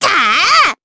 Miruru-Vox_Attack3_jp.wav